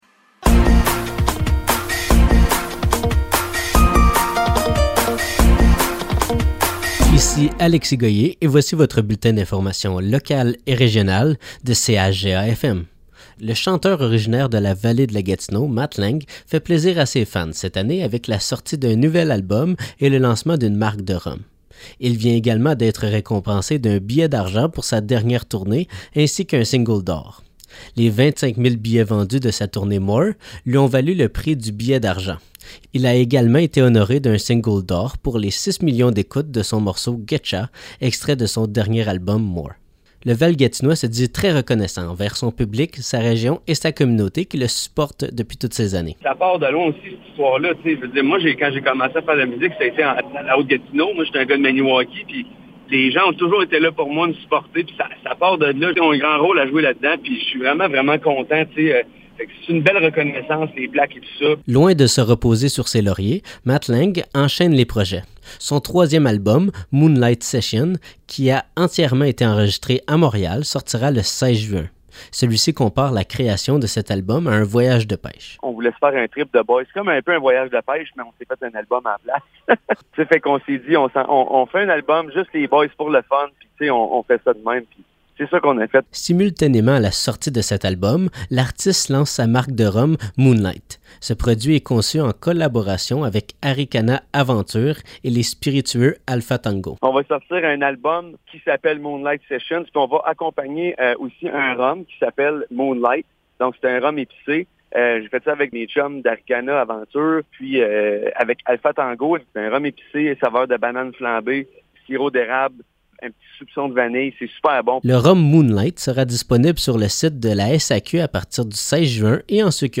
Nouvelles locales - 31 mai 2023 - 12 h